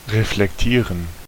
Ääntäminen
US US : IPA : /ˈɹiflɛkt/ UK : IPA : /ɹəˈflɛkt/